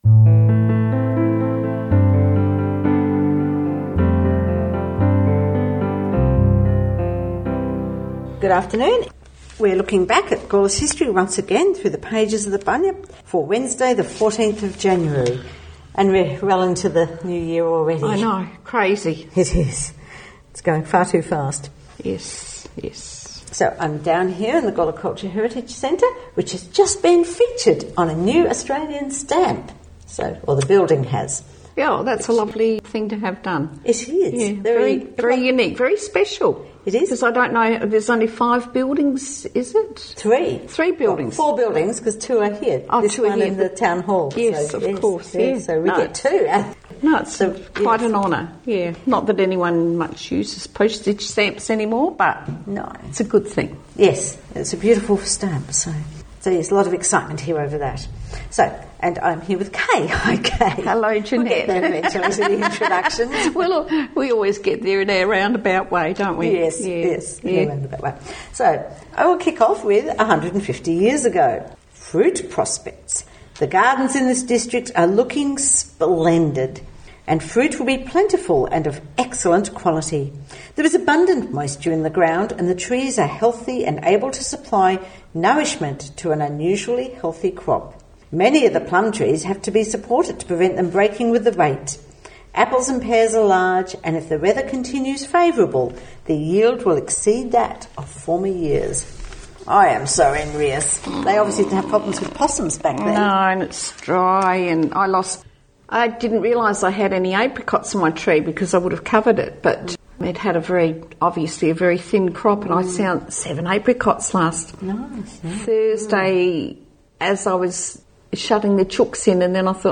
Opening and closing music taken from A Tribute to Robbie Burns by Dougie Mathieson and Mags Macfarlane